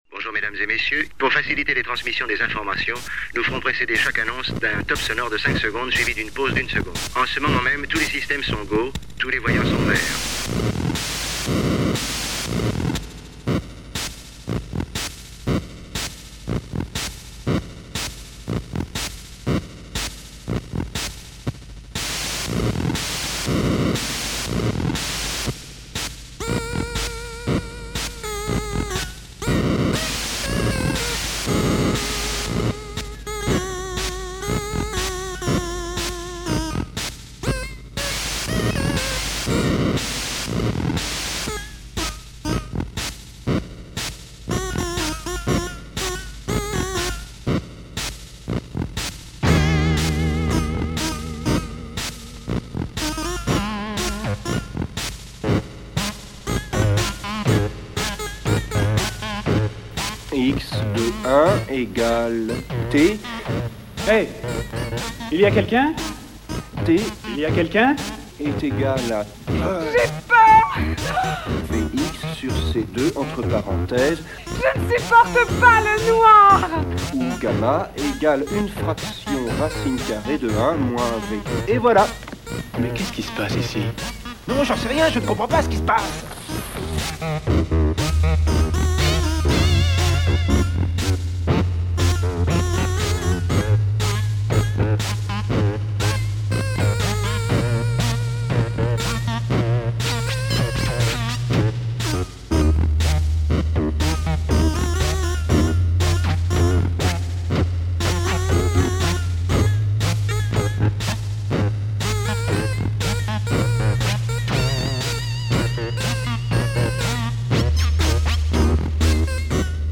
Blog,Mix,Mp3,collage sonore